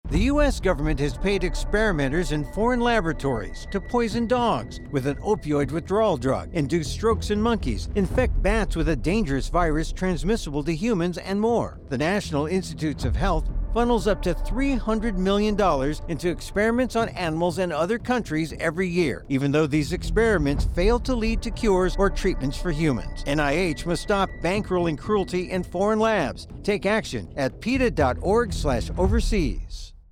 Instructions for Downloading This Radio PSA Audio File